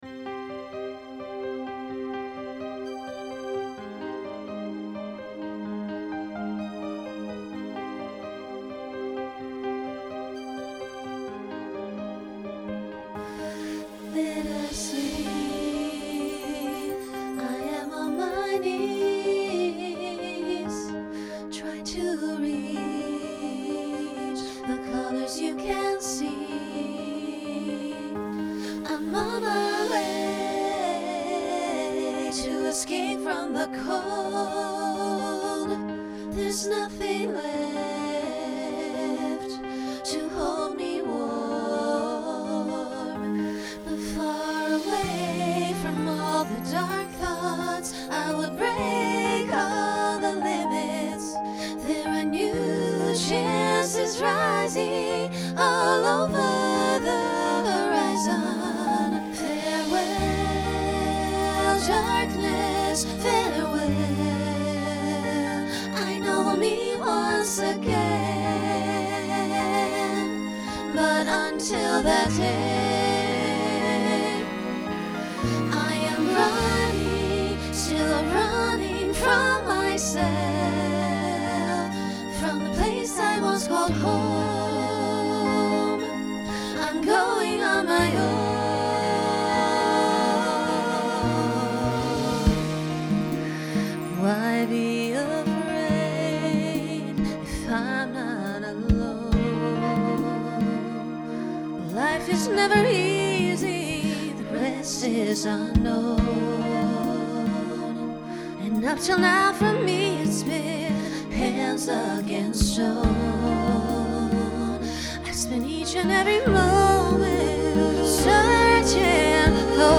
Genre Pop/Dance
Function Ballad Voicing SSA